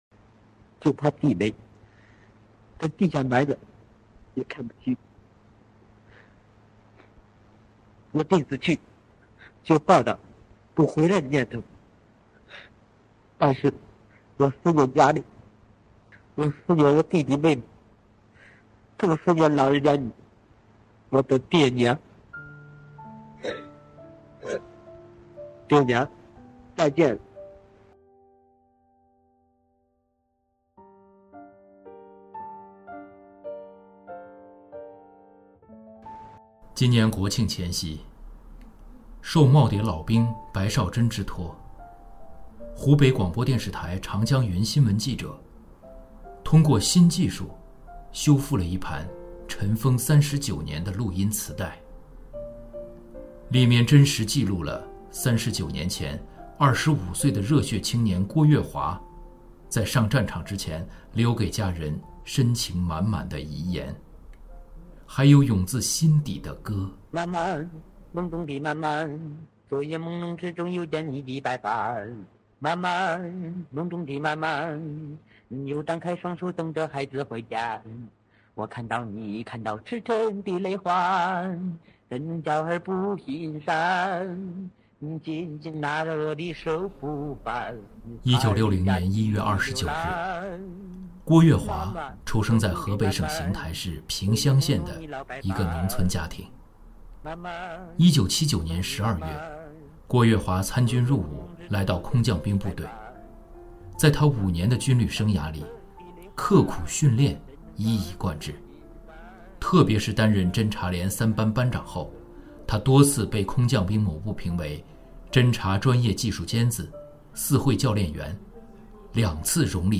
尘封39年，记录英雄遗言的磁带重获“新声”